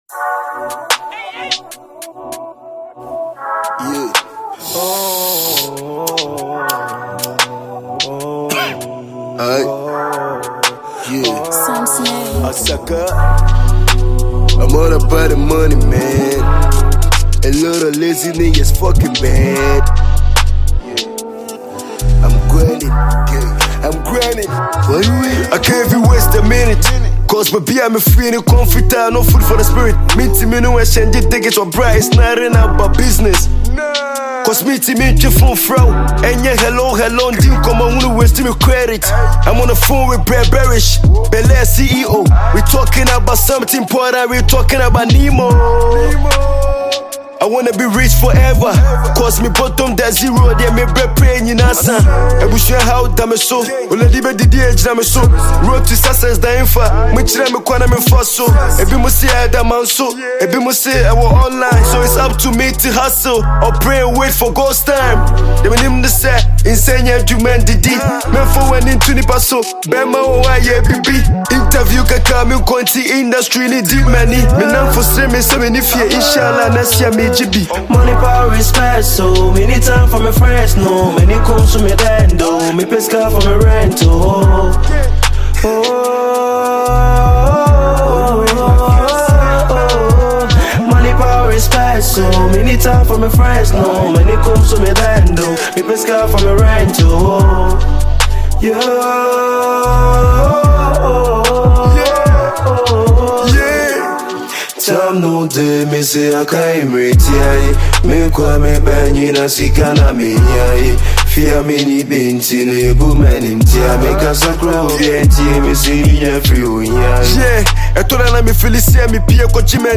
Multi-talented Ghanaian rapper and songwriter